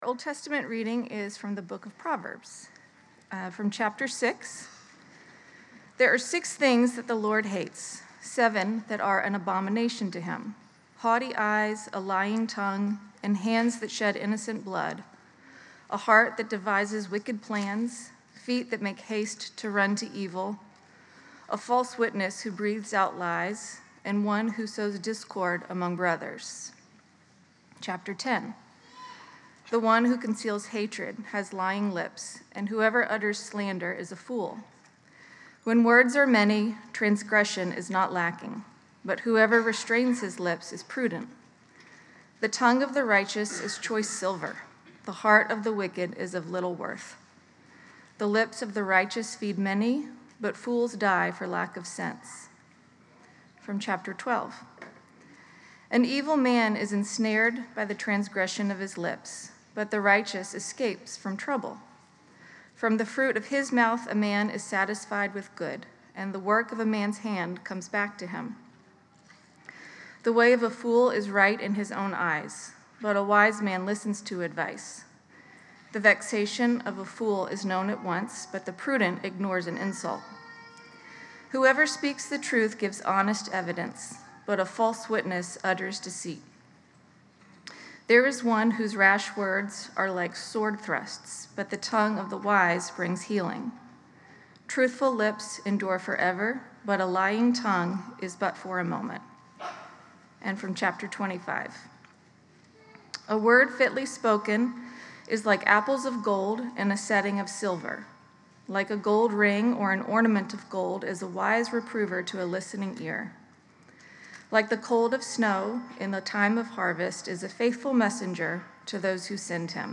This sermon explores the power of the tongue in Proverbs, showing how words can either destroy relationships or bring healing. By finding our identity in Christ’s love, we are transformed to speak with the wisdom and kindness that restores others.